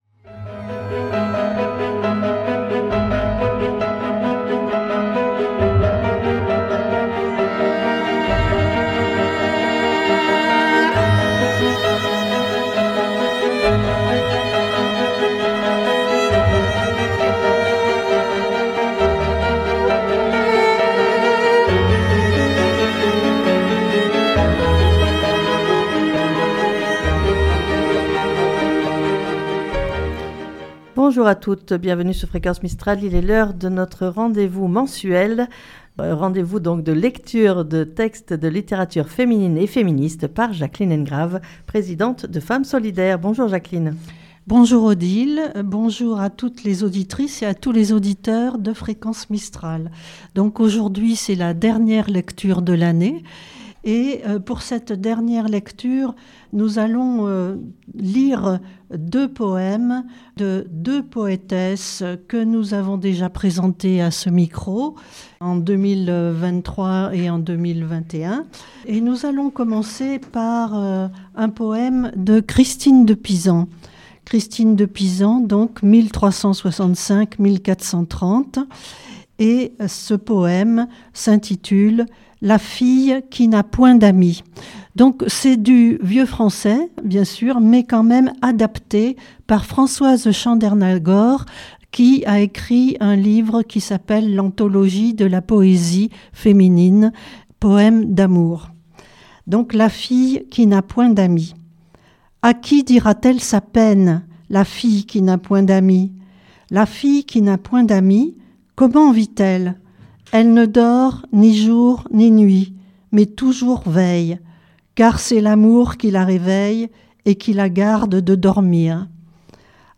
Lecture de textes de littérature féminine et féministe N°10